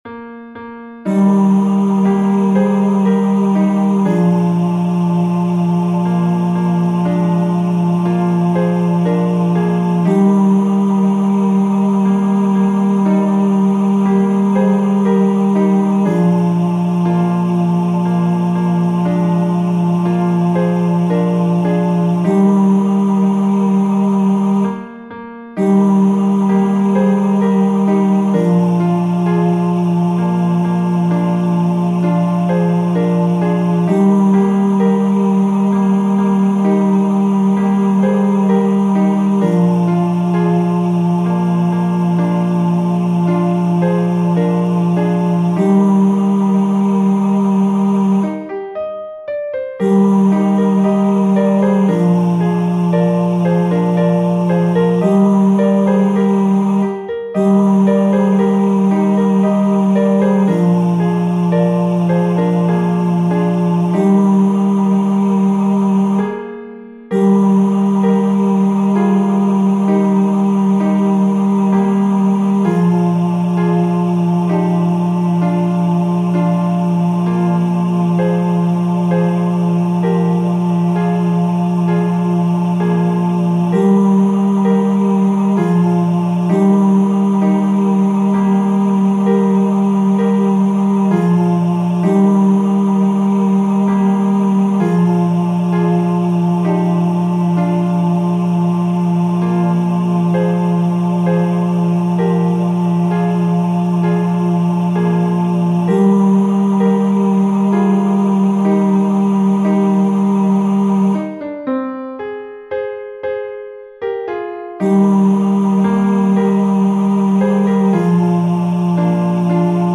Demos zum Herunterladen